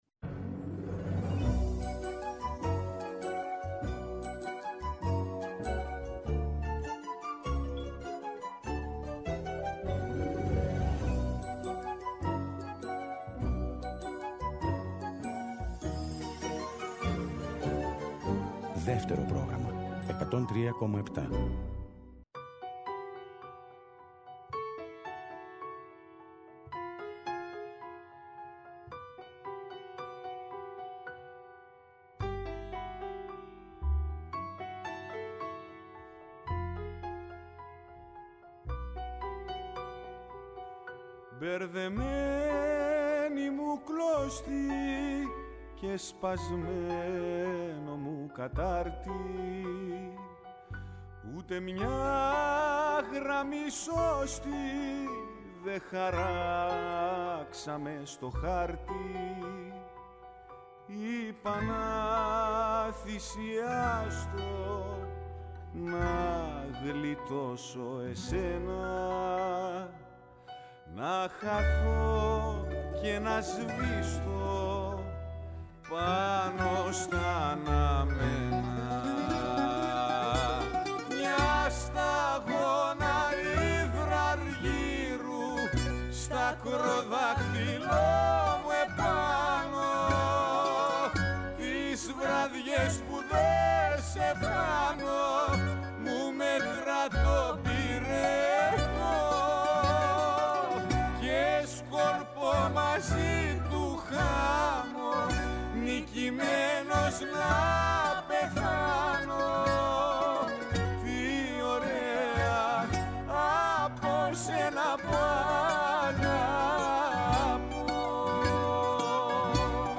ΔΕΥΤΕΡΟ ΠΡΟΓΡΑΜΜΑ Συνεντεύξεις